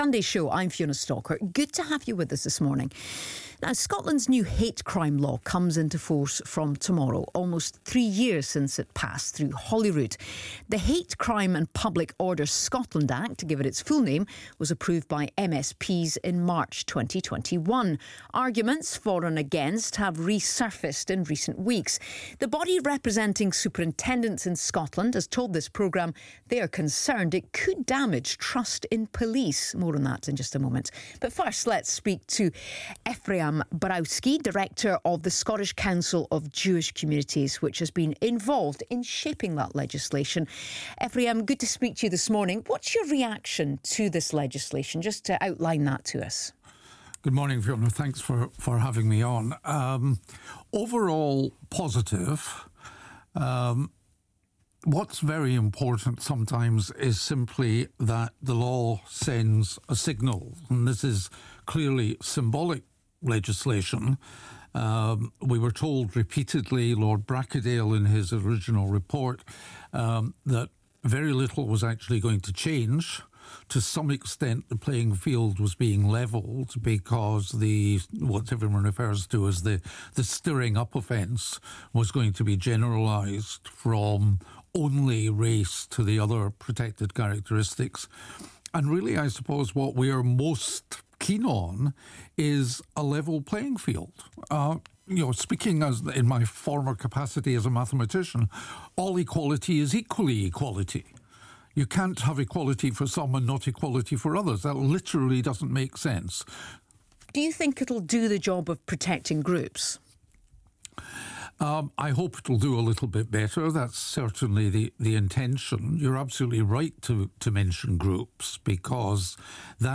BBC Scotland interview about the Hate Crime and Public Order (Scotland) Act